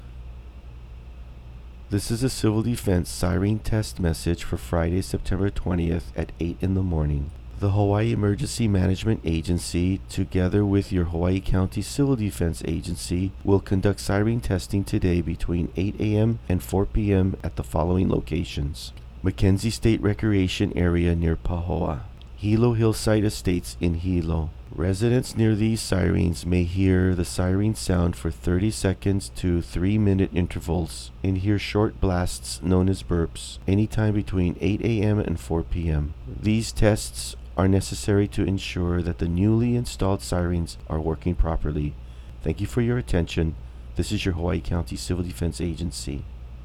HAWAIʻI ISLAND - The Hawaiʻi County Civil Defense issued an audio alert today, saying sirens in two locations could sound at anytime between 8 a.m. and 4 p.m.
Hawaiʻi County Civil Defense also issued an audio message about the siren testing.